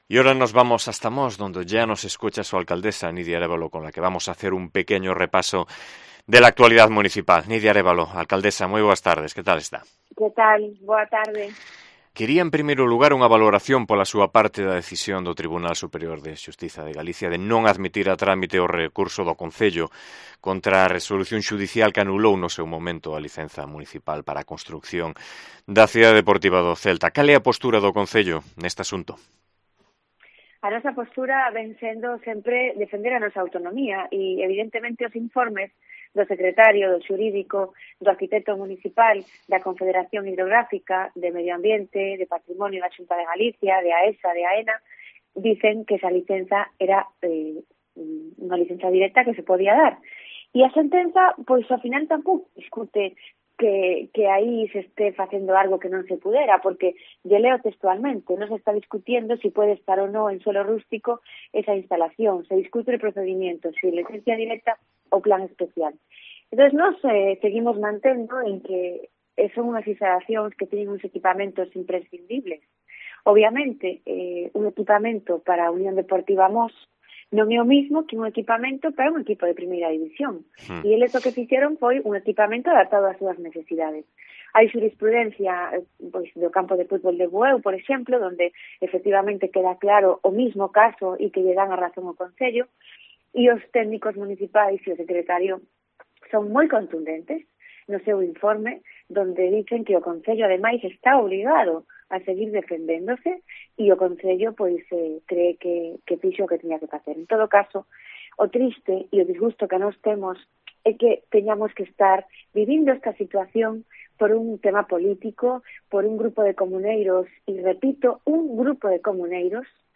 ENTREVISTA
Entrevista con Nidia Arévalo, alcaldesa de Mos